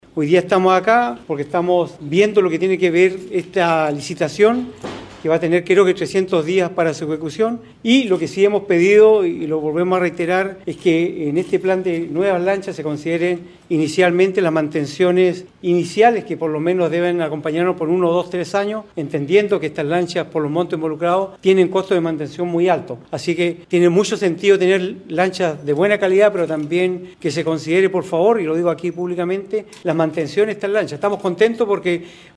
En este contexto, el alcalde de Quinchao y presidente de la Asociación de Municipios de Chiloé, René Garcés, valoró la iniciativa; sin embargo, hizo un llamado al Gobierno a hacerse cargo de la mantención al menos inicial de las embarcaciones debido a los altos costos.